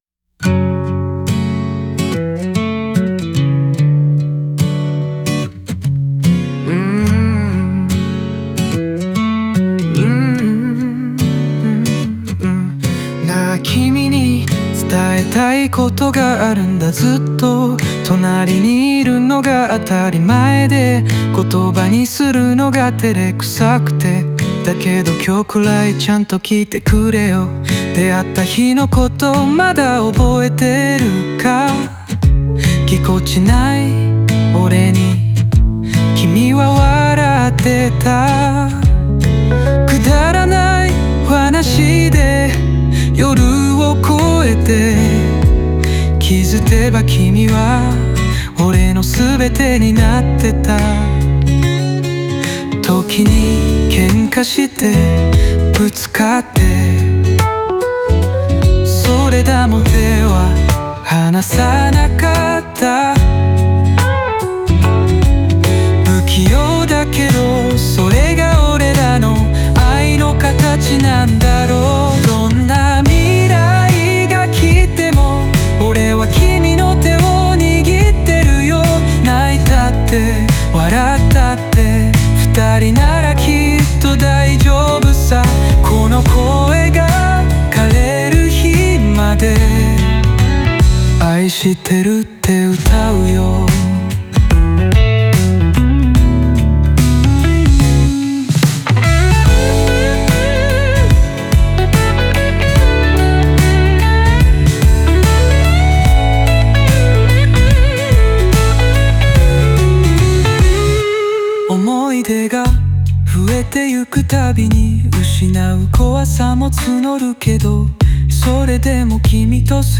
時が経ち、年老いても変わらぬ想いを持ち続けることを願う、温かくて力強いラブソングです。